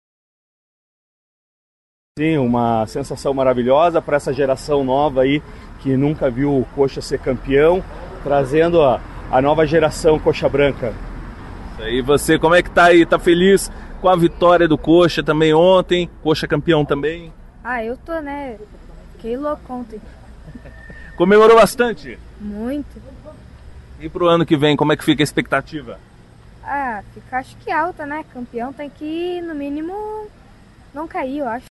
Após a recepção, a equipe embarcou nos ônibus sob muita festa e foi acompanhada por vários carros de torcedores até a região do Estádio Couto Pereira, na capital.